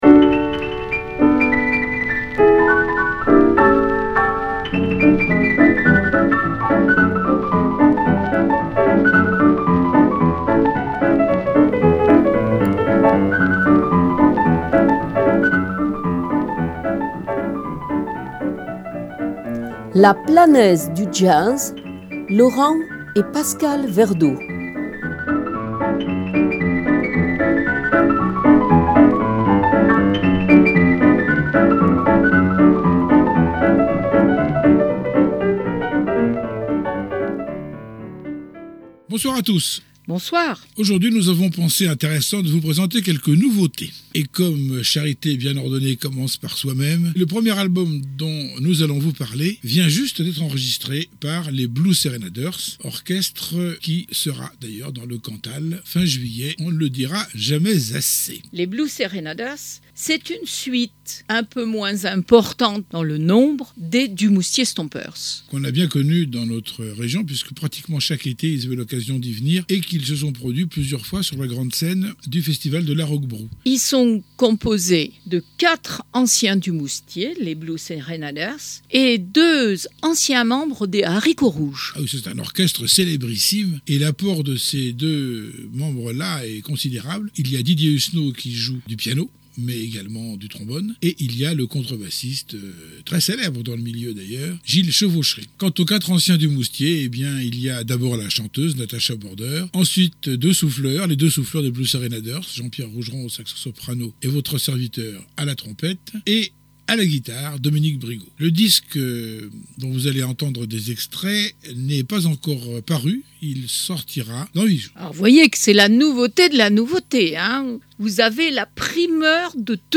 Emission sur le Jazz